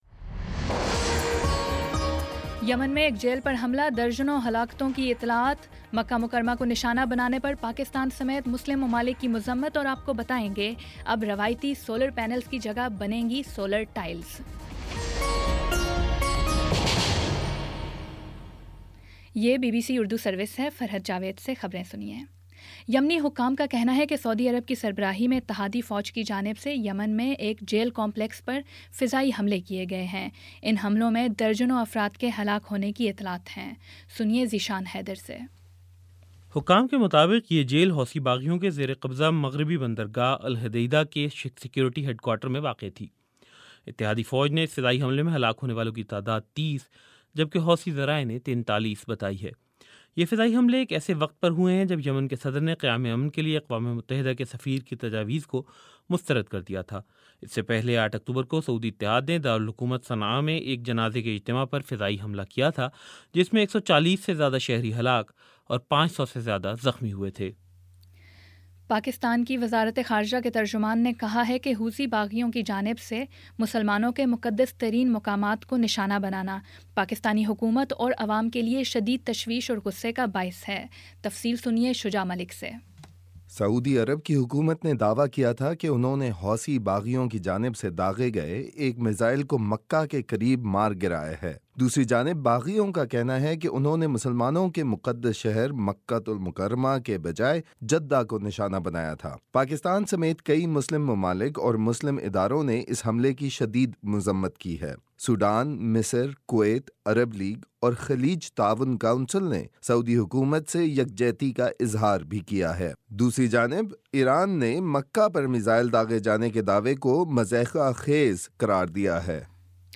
اکتوبر 30 : شام پانچ بجے کا نیوز بُلیٹن